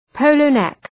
{‘pəʋlə,nek} (Ουσιαστικό) ● ζιβάγκο